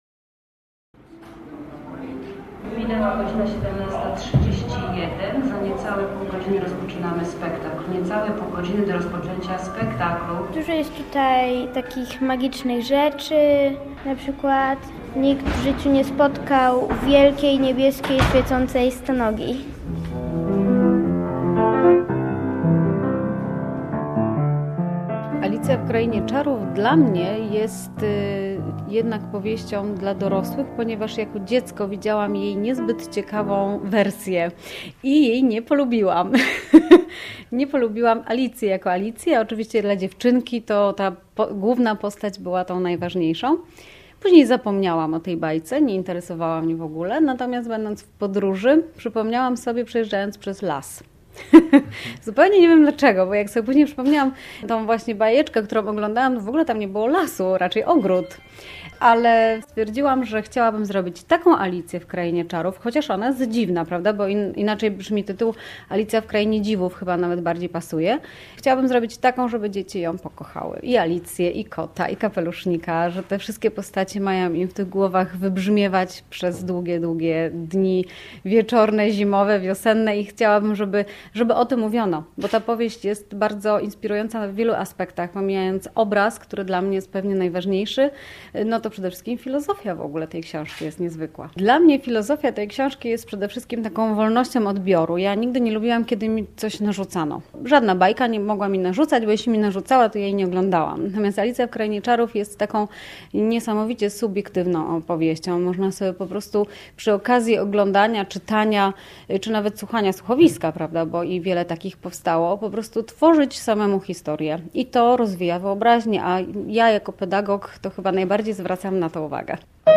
"Alicja w Krainie Czarów"- reportaż